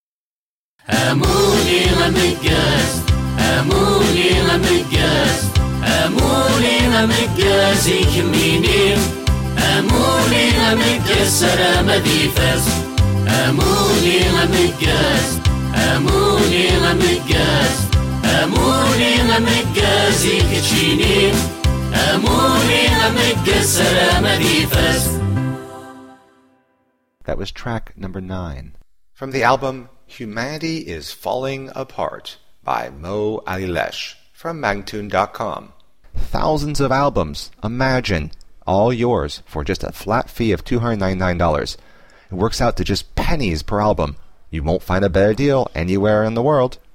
Music of the mountains of kabylia.
Tagged as: World, Folk, Arabic influenced, World Influenced